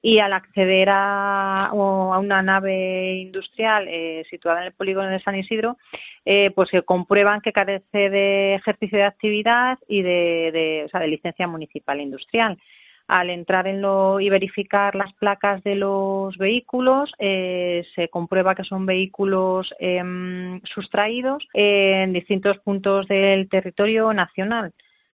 Nos lo cuenta la alcaldesa en una semana en la que también hemos conocido el desmantelamiento en la localidad de un taller de vehículos robados
La alcaldesa de Seseña y concejal de Seguridad Ciudadana, Silvia Fernández, ha explicado en la sintonía de la Cadena COPE que el consistorio está reforzando la seguridad en el municipio con el aumento de las patrullas de la Policía Local, sobre todo durante los fines de semana.